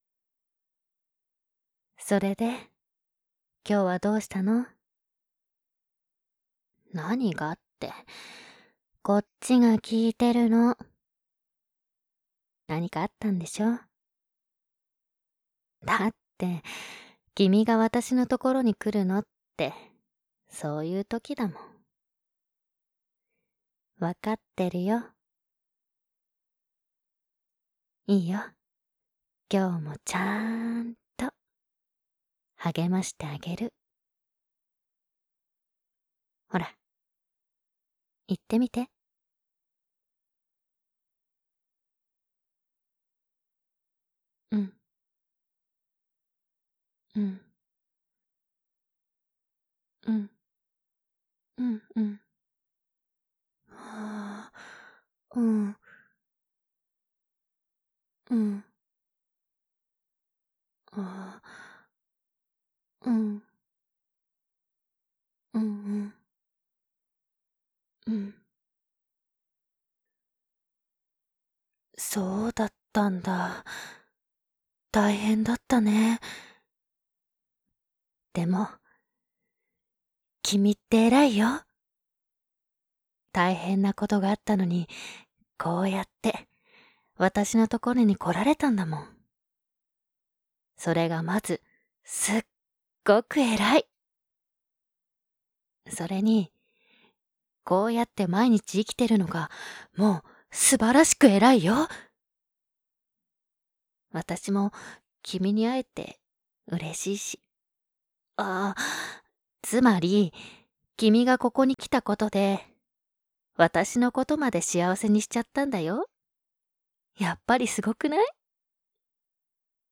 纯爱/甜蜜 日常/生活 温馨 萌 健全 感动 皆大欢喜 姐姐 AI